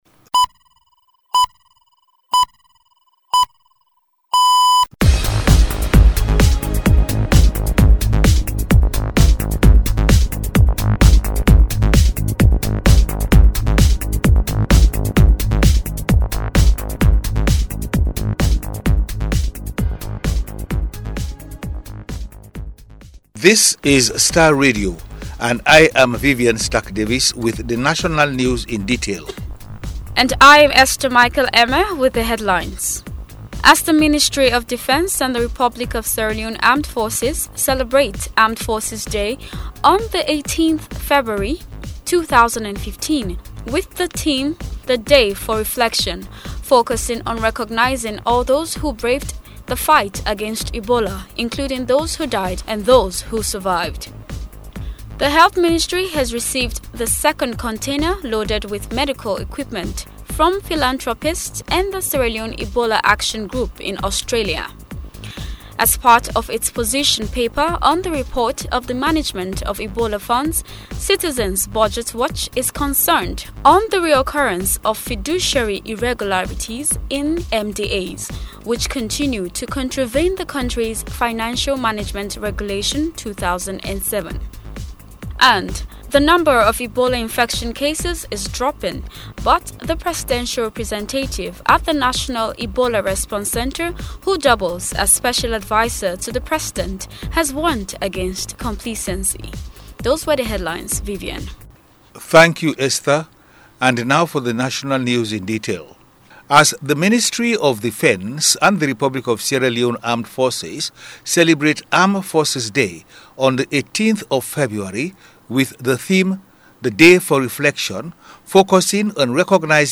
ABC interview